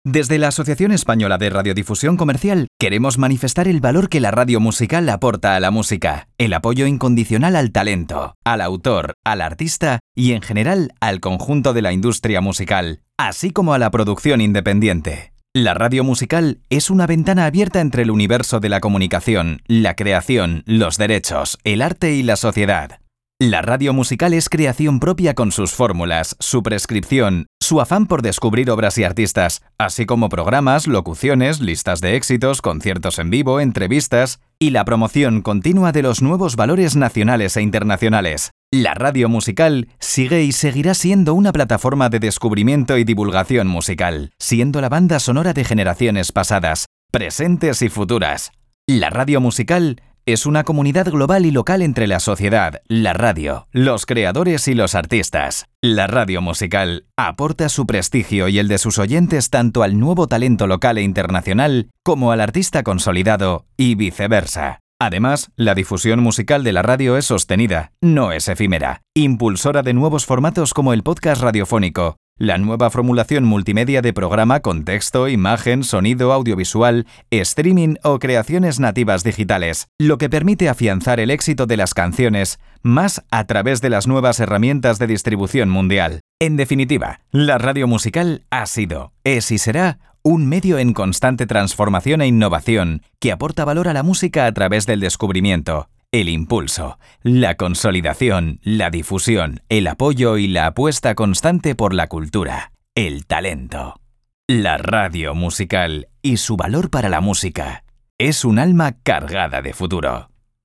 Hemos locutado el mensaje institucional de la Asociación Española de la Radiodifusión Comercial